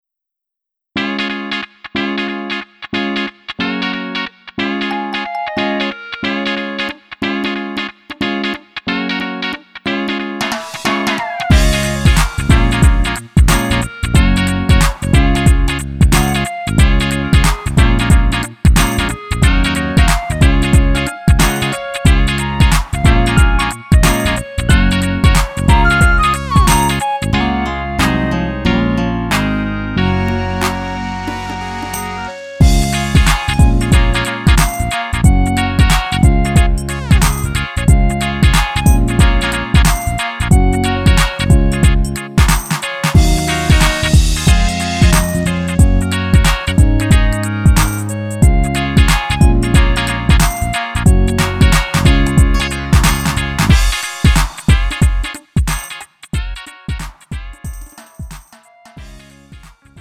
음정 -1키 3:02
장르 가요 구분